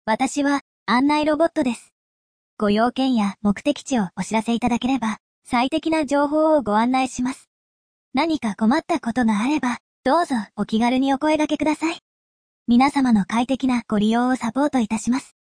AI音声合成・音声読み上げ（WEB テキスト）ソフトのReadSpeaker（リードスピーカー）